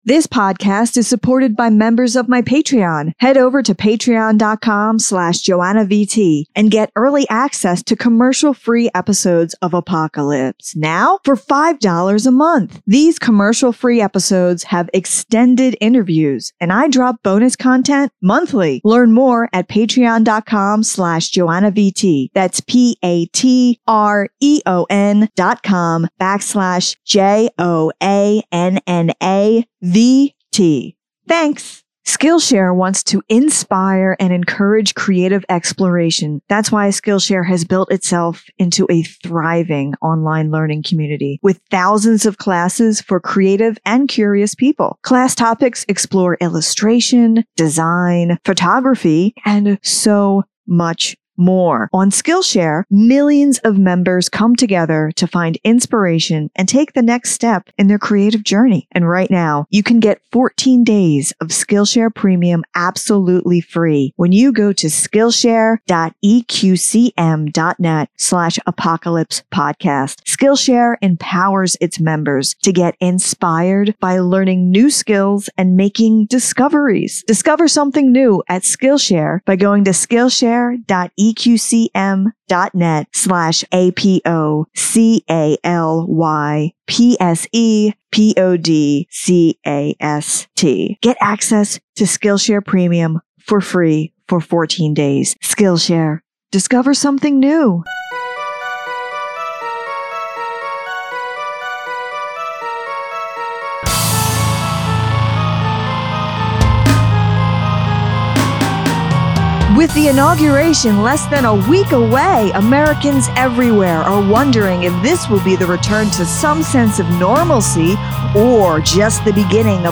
This is an important conversation, and it reminds us of the damage that the Trump Administration caused the LGBTQ+ community and makes us hopeful for the future.